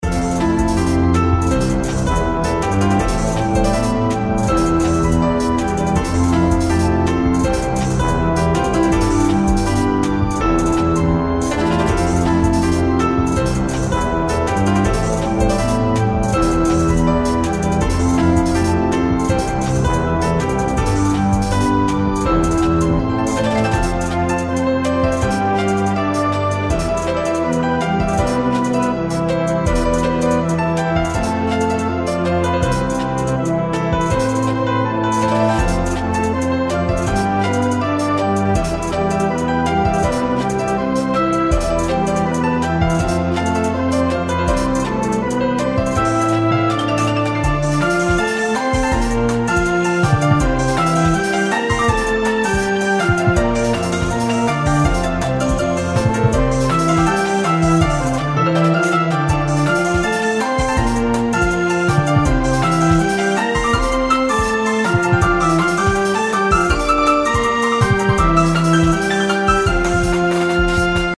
それぞれ１ループの音源です♪